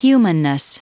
Prononciation du mot humanness en anglais (fichier audio)
humanness.wav